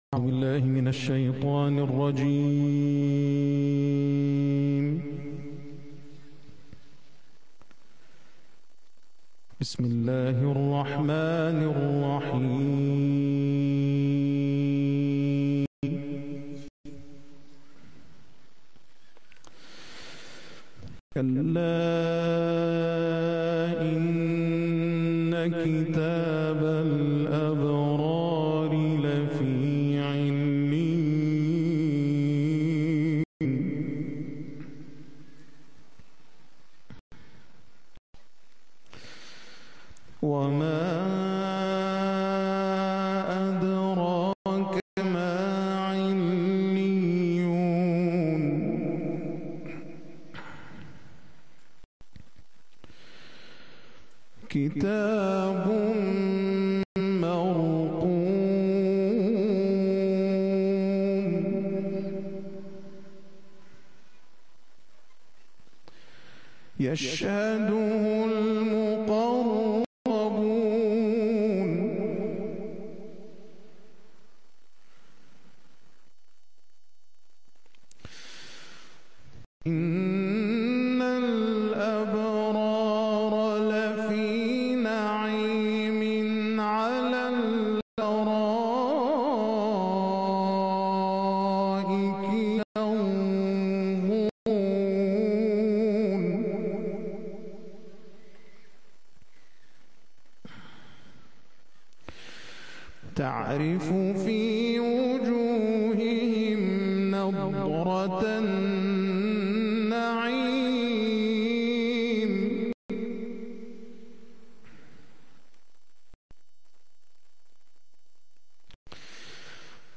قرائت قرآن کریم